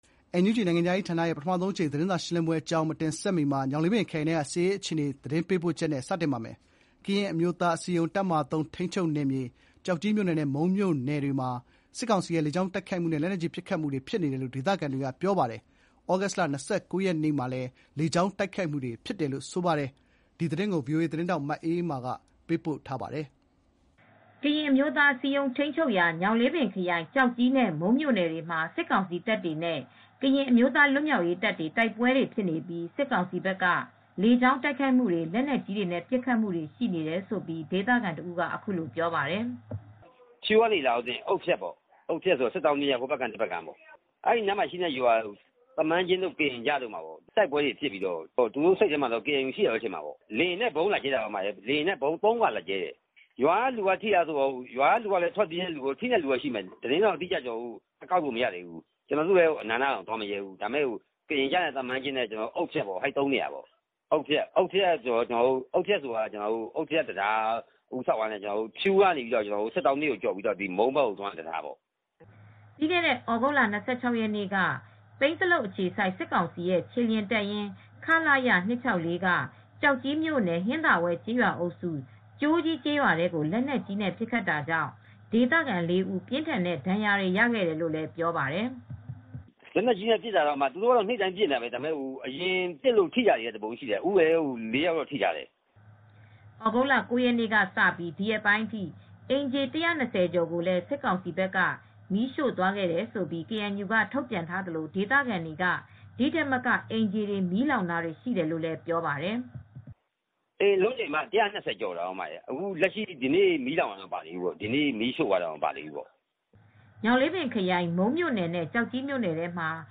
ကရင်အမျိုးသားအစည်းအရုံး (KNU) ထိန်းချုပ်တဲ့ ညောင်လေးပင်ခရိုင် ကျောက်ကြီးနဲ့ မုန်းမြို့နယ်တွေမှာ စစ်ကောင်စီတပ်တွေနဲ့ ကရင်အမျိုးသား လွတ်မြောက်ရေးတပ်တွေကြား တိုက်ပွဲတွေ ဖြစ်နေပြီး စစ်ကောင်စီဘက်က လေကြောင်းက ဗုံးကြဲတိုက်ခိုက်တာ၊ လက်နက်ကြီးနဲ့ ပစ်ခတ်တာတွေ ရှိနေတယ်လို့ ဒေသခံတဦးက ဗွီအိုအေကို ပြောပါတယ်။